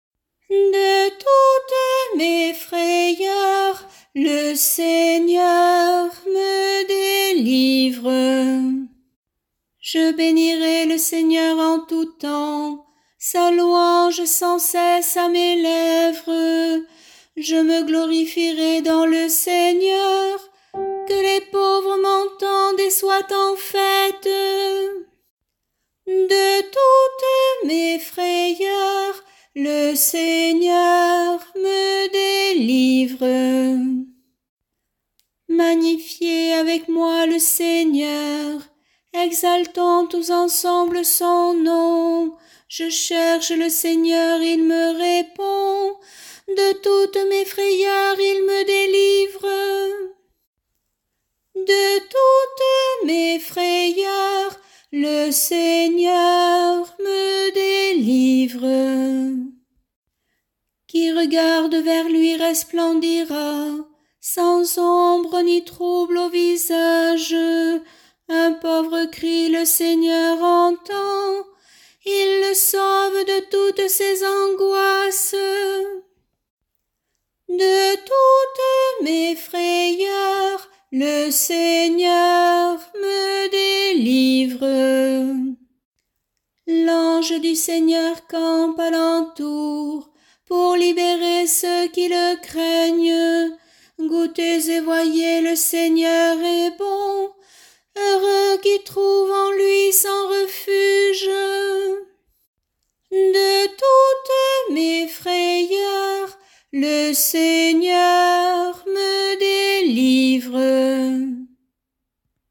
Chorale psaumes année A – Paroisse Aucamville Saint-Loup-Cammas